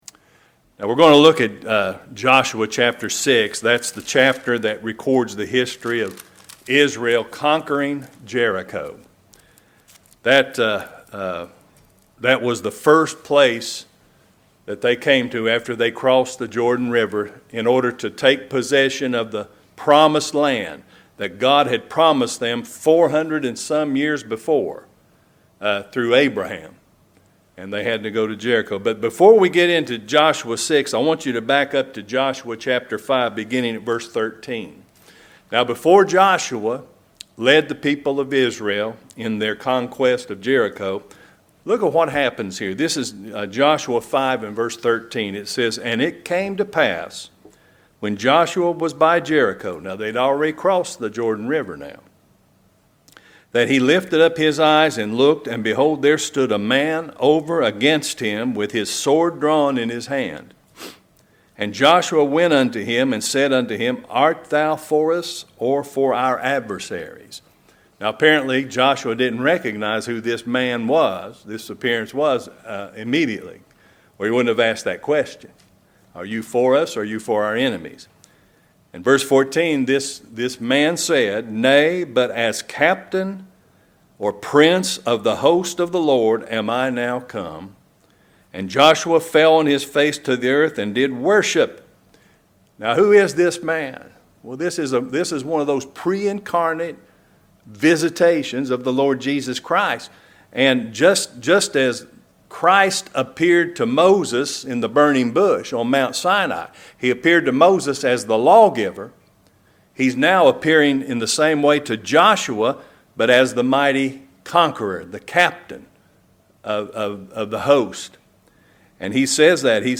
Christ, the Mighty Conqueror | SermonAudio Broadcaster is Live View the Live Stream Share this sermon Disabled by adblocker Copy URL Copied!